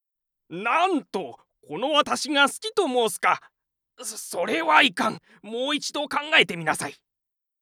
パロディ系ボイス素材
元ネタが何かしらの作品中に含まれているor作品にまつわるタイプの声素材